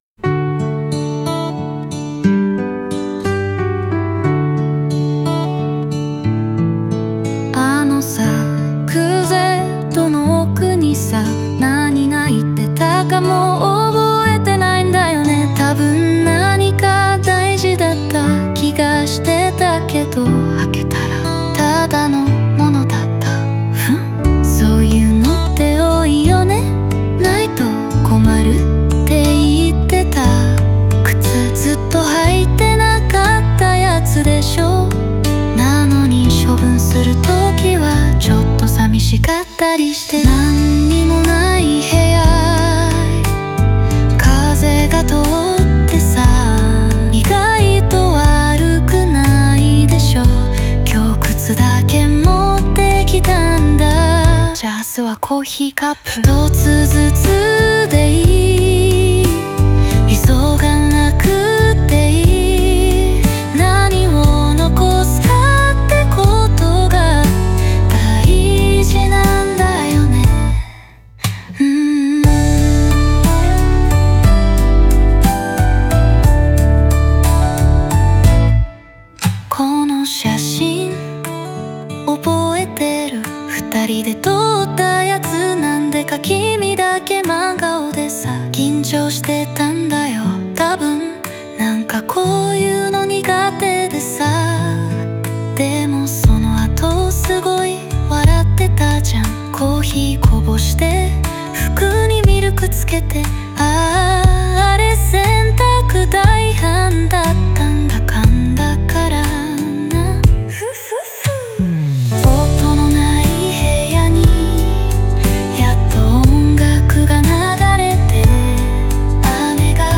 オリジナル曲♪